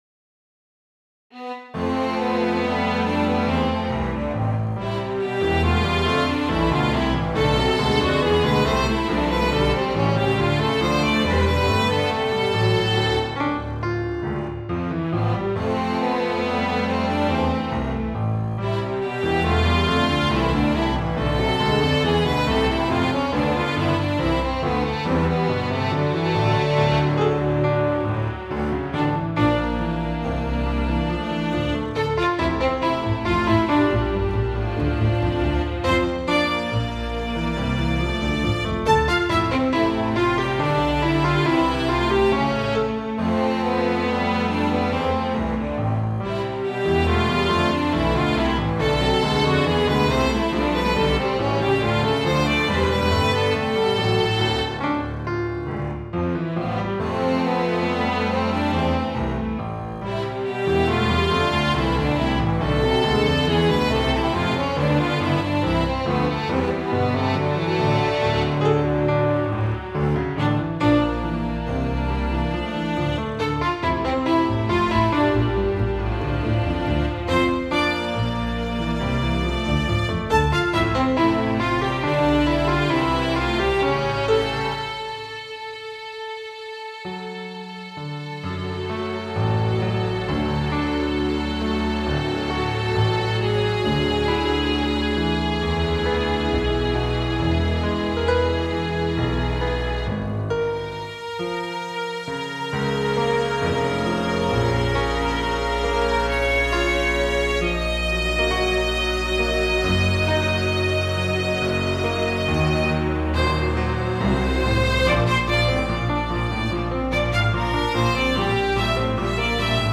• Slightly randomized note timings and velocities on the piano;
• Auto-generated pitch-bend events per note for bowed string instruments;
• Slightly randomized note timings and pitch-bend events for bowed instruments;
• Made some Mid/Side EQ adjustments in order to tame low-mid build-up.
The slight randomizations made things a tad more "humanized" and the overall soundscape is now clearer I think.